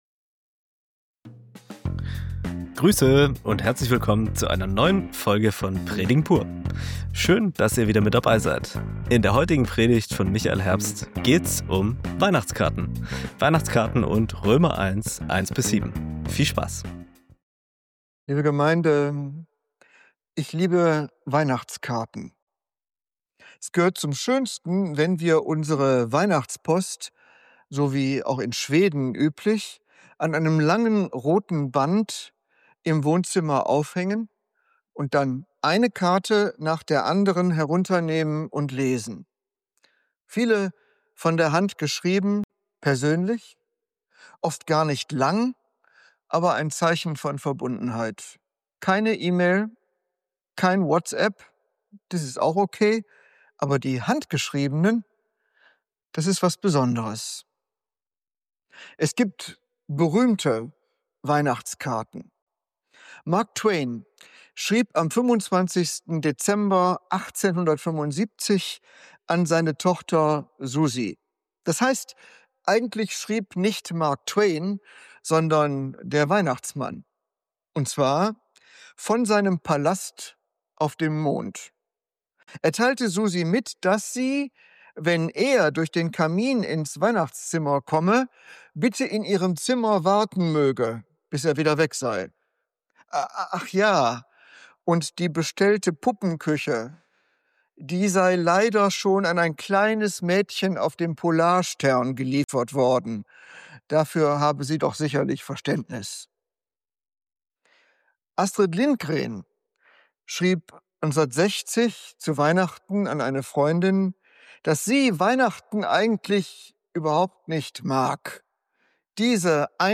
Die Predigt nimmt eine ungewöhnliche Weihnachtskarte in den Fokus – nämlich die, die Paulus an die Gemeinde in Rom schreibt.